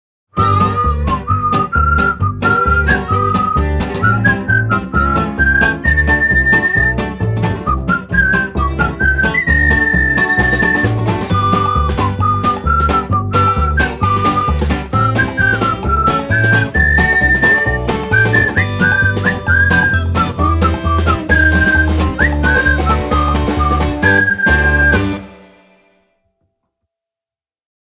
INSTRUMENTAL ACOUSTIC / CLASSIC